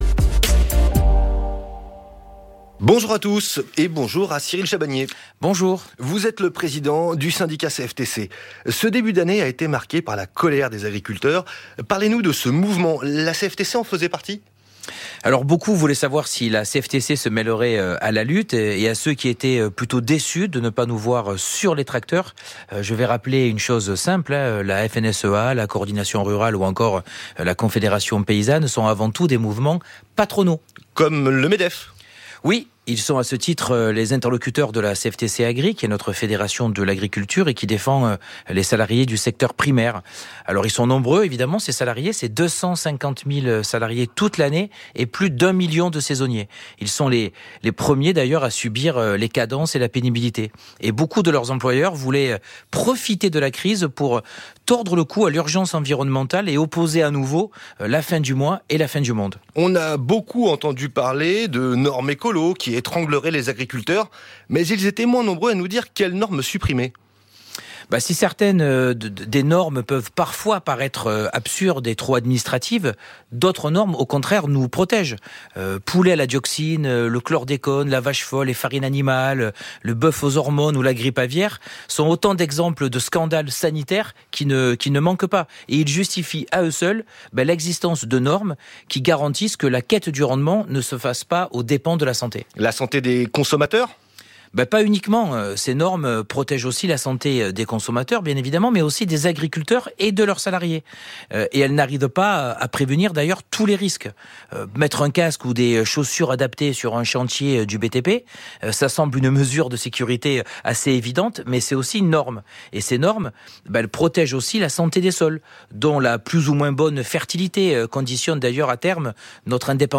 Le président de la CFTC Cyril Chabanier a pu s’exprimer dans le cadre de l’émission Expression directe, diffusée ce 16 mars sur France Inter.